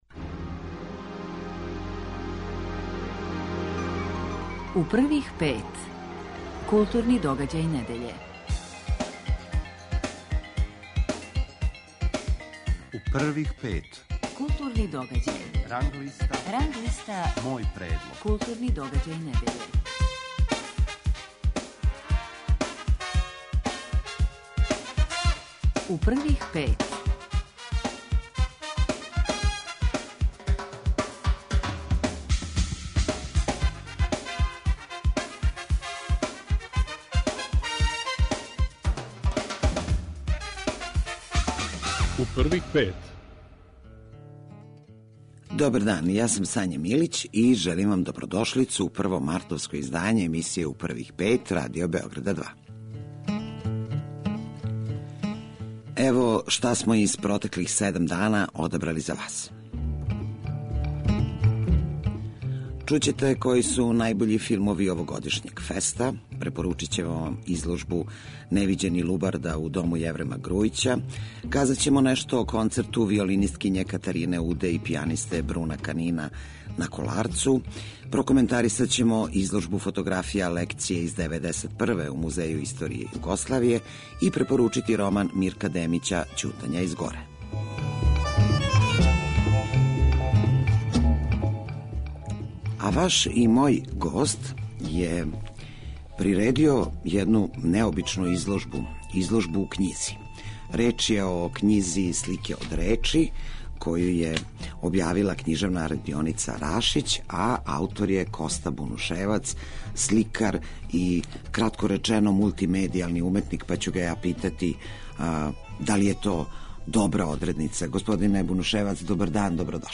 Новинари и критичари Радио Београда 2 издвајају најбоље, најважније културне догађаје у свим уметностима у протеклих седам дана и коментаришу свој избор.